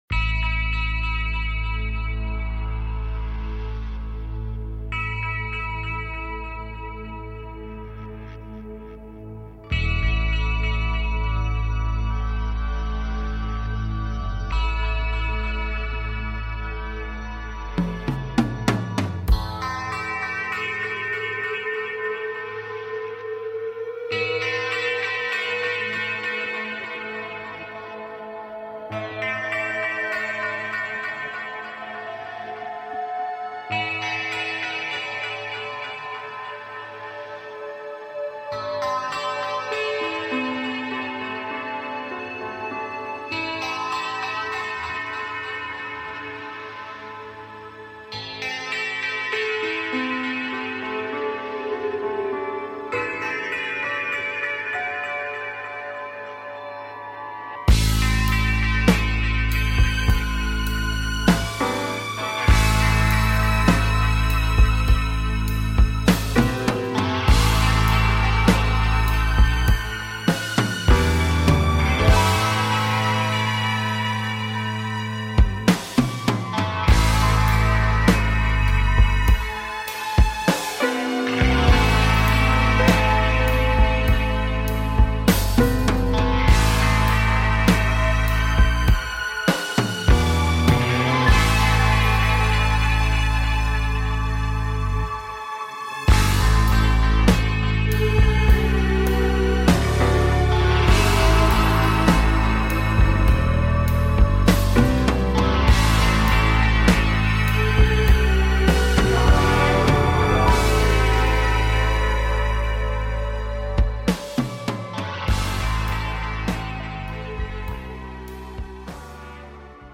Reviewing listener projects and answering listener calls